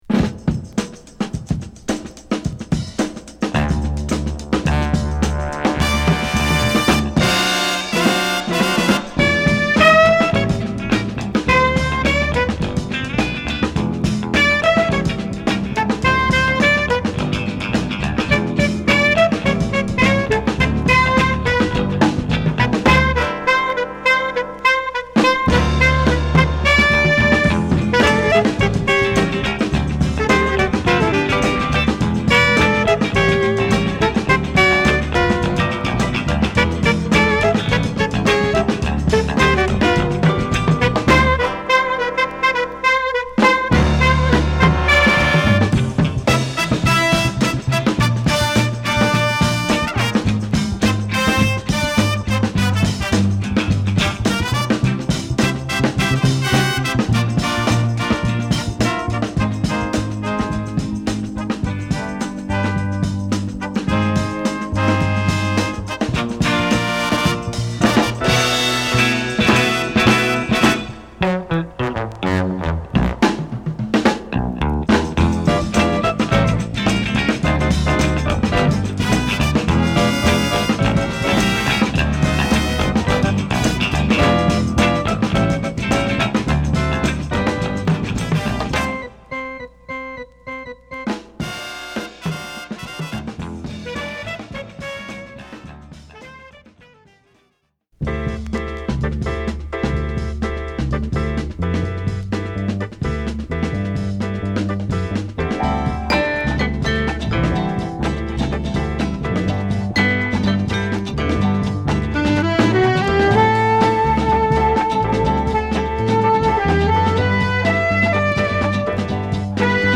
ばっちりドラムブレイクから始まるナイスカヴァーA2